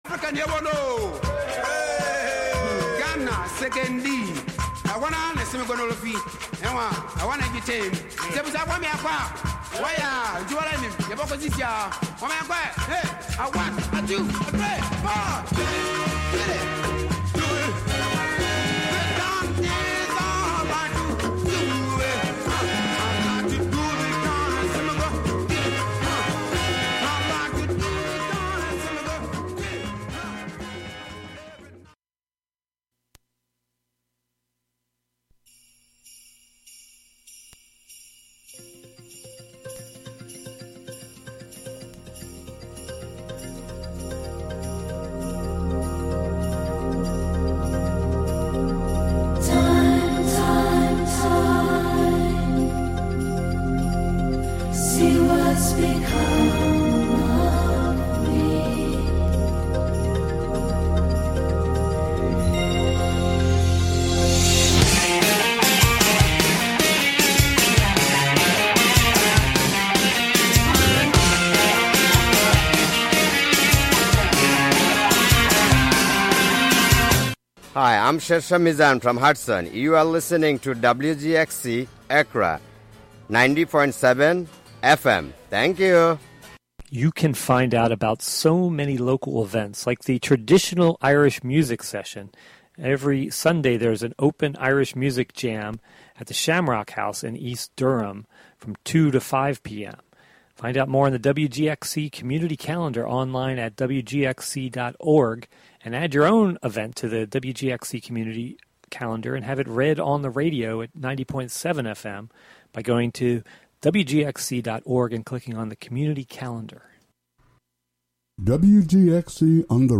Classic R&B